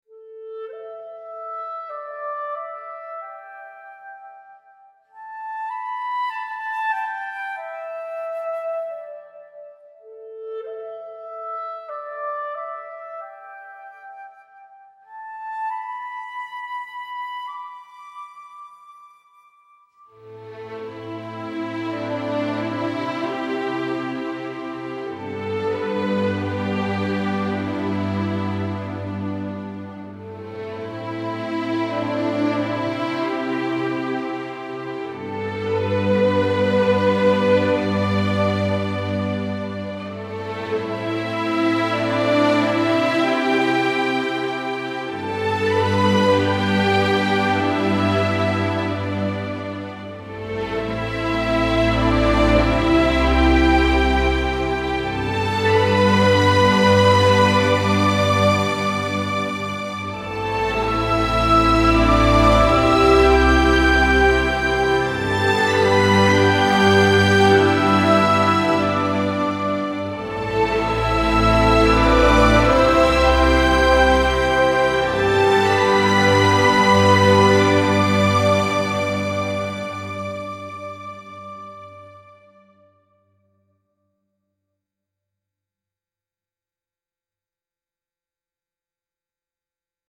classic memorial orchestral piece with nostalgic strings and gentle oboe